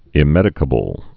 (ĭ-mĕdĭ-kə-bəl)